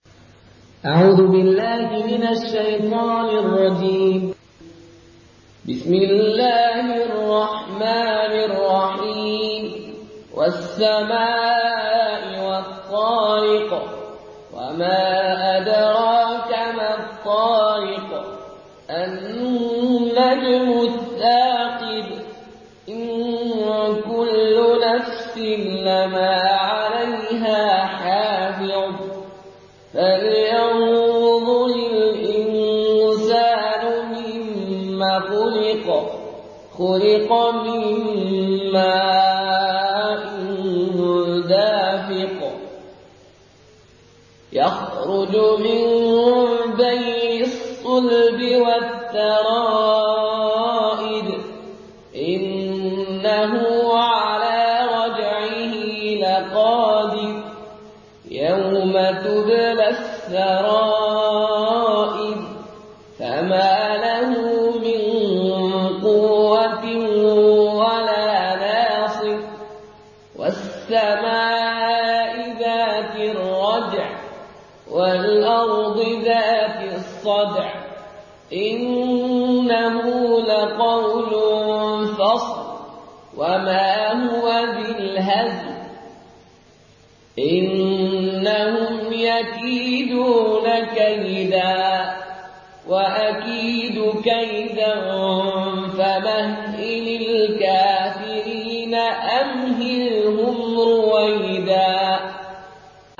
in Qaloon Narration
Murattal Qaloon An Nafi